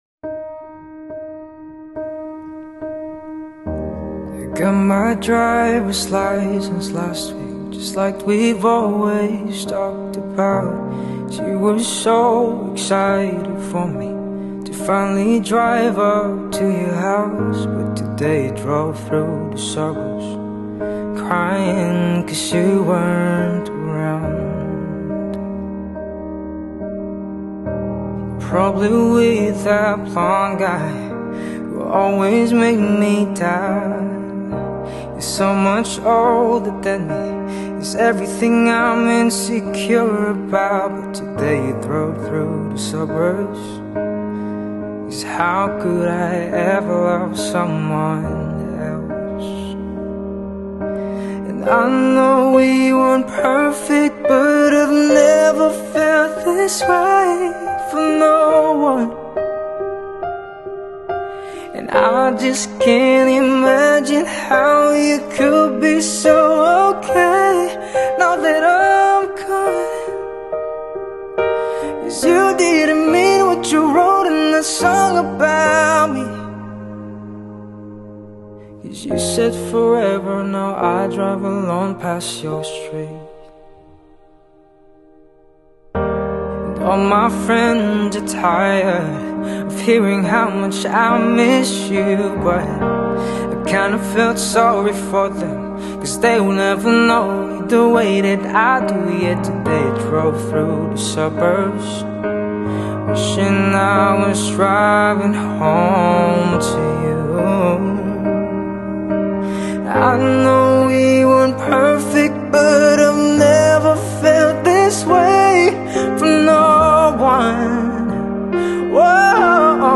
Vocals | Guitar | Looping | Piano | DJ
soothing and soulful singing style